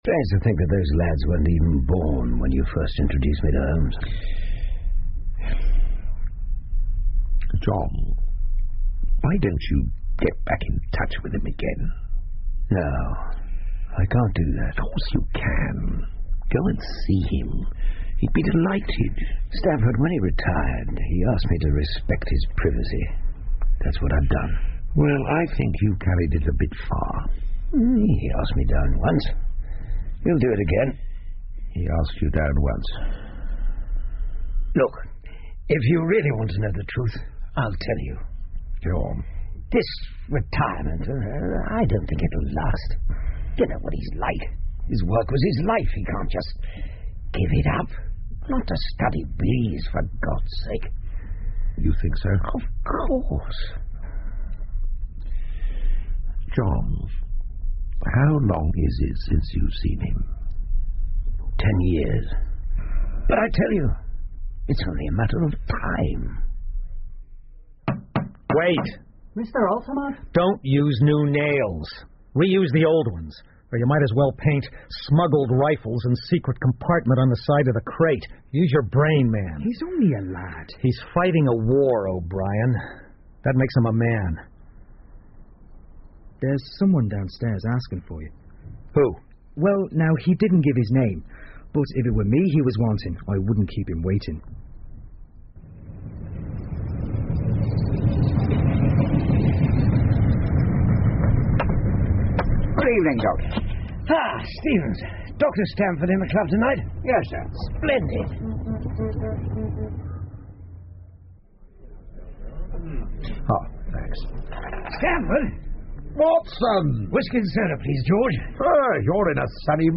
福尔摩斯广播剧 His Last Bow 2 听力文件下载—在线英语听力室